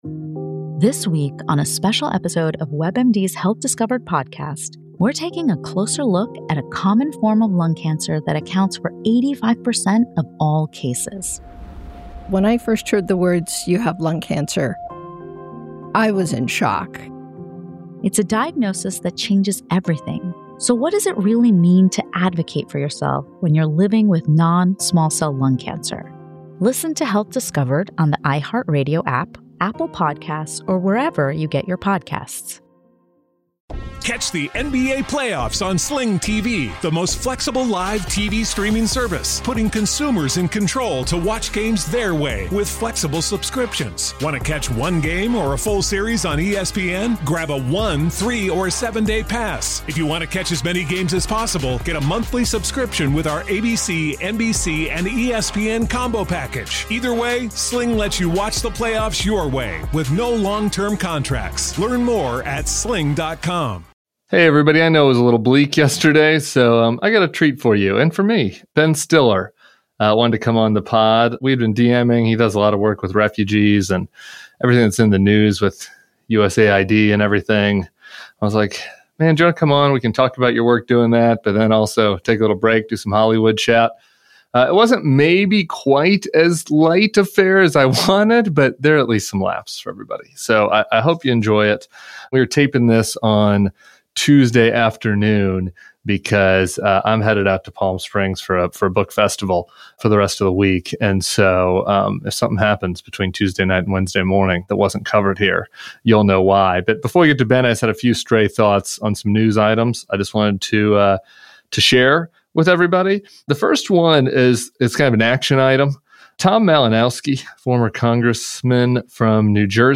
Ben Stiller talks with Tim about metaphysics, avoiding politics in public, and advocating for the millions of people displaced around the world. Plus, the origin story of Severance, Adam Scott, John Turturro, and whether the show is a metaphor for life itself.
Ben Stiller joins Tim Miller. show notes: Watch Severance The Albert Brooks film, 'Real Life.'